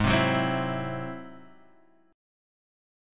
Ab7b13 Chord